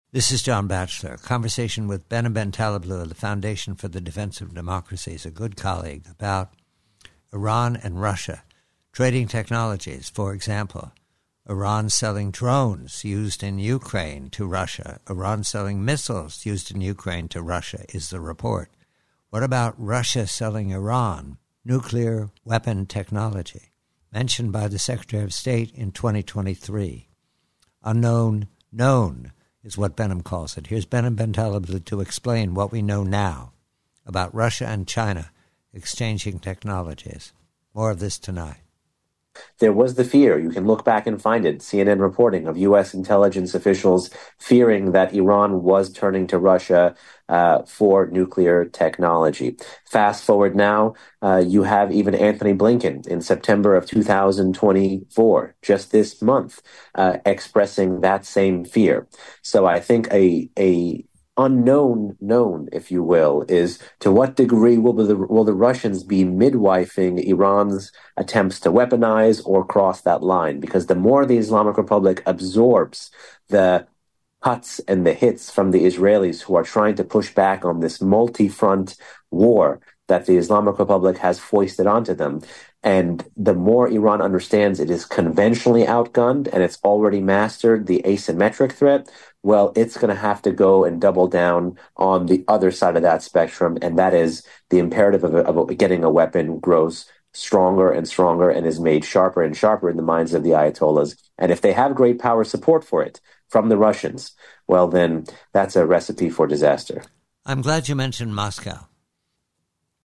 PREVIEW: IRAN: RUSSIA: NUCLEAR WEAPONS: Conversation